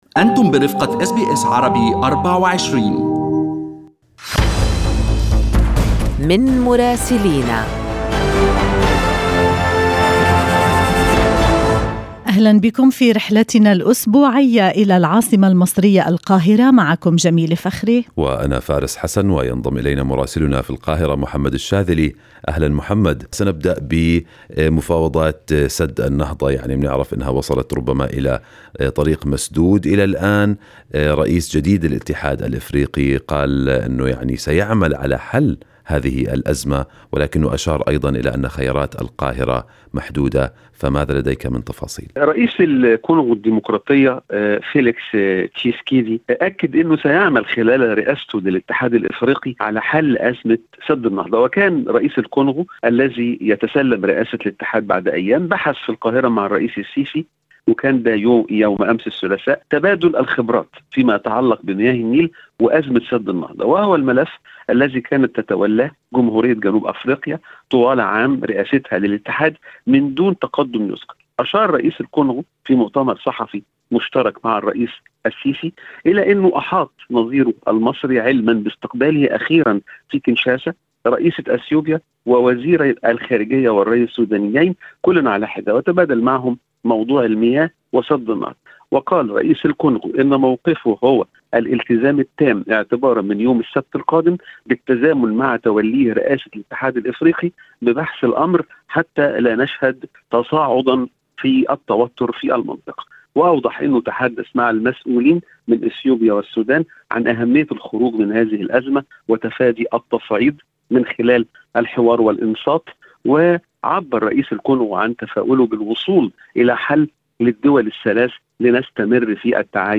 من مراسلينا: أخبار مصر في أسبوع 3/2/2021